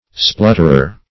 Splutterer \Splut"ter*er\ (spl[u^]t"t[~e]r[~e]r)